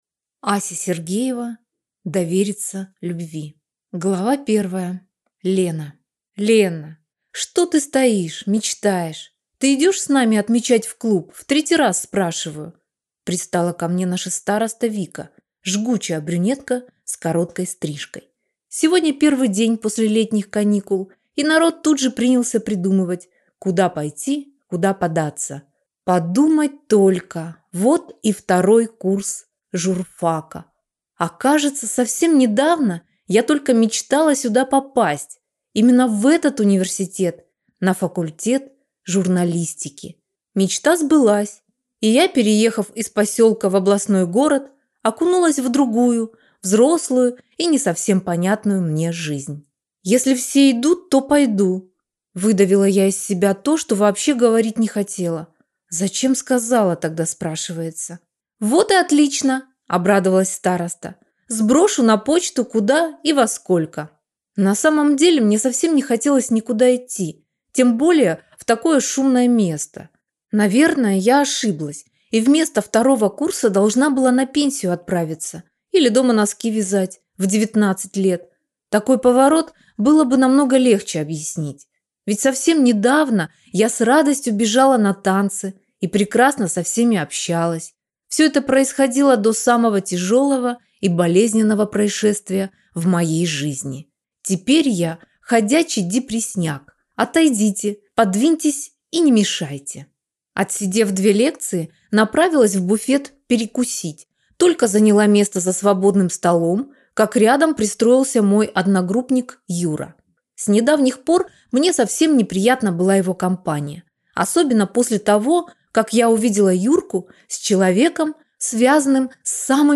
Аудиокнига Довериться любви | Библиотека аудиокниг